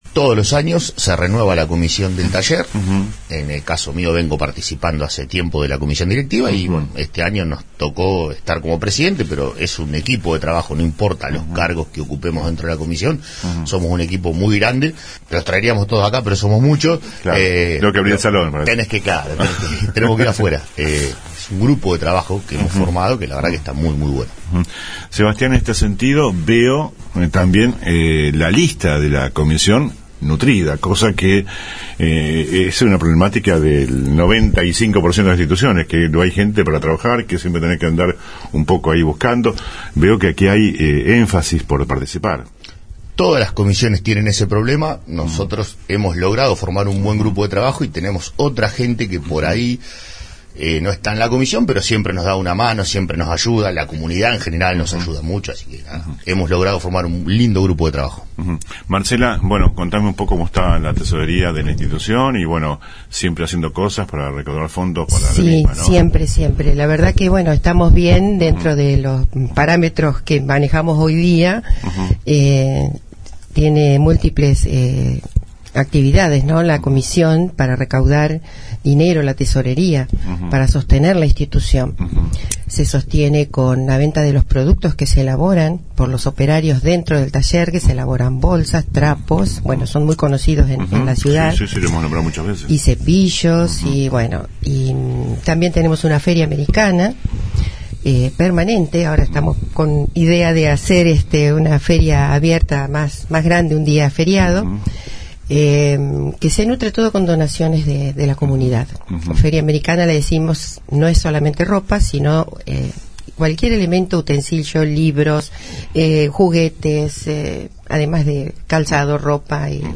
Audio completo de la entrevista.